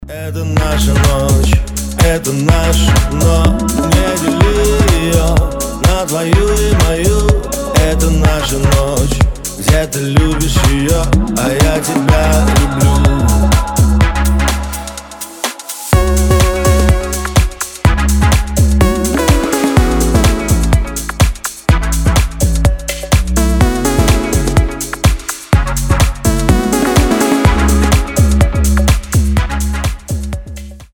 • Качество: 320, Stereo
мужской вокал
dance
Electronic
club
чувственные
клубняк